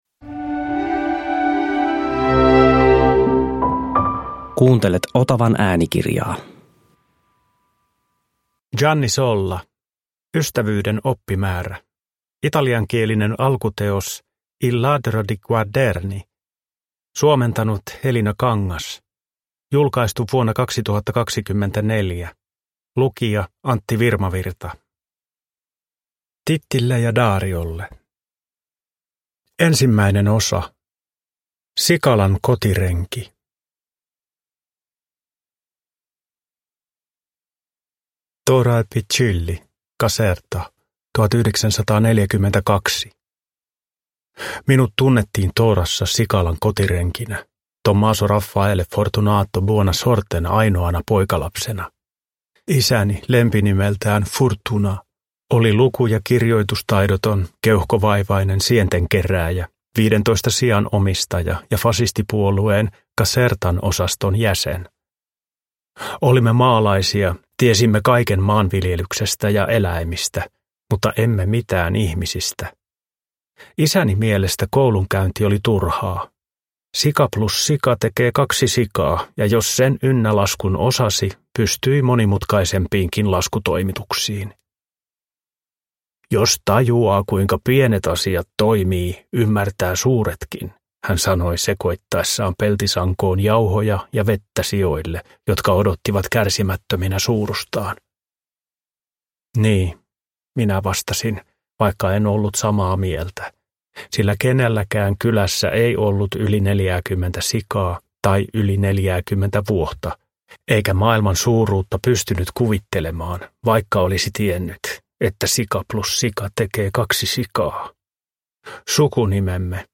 Ystävyyden oppimäärä – Ljudbok
Uppläsare: Antti Virmavirta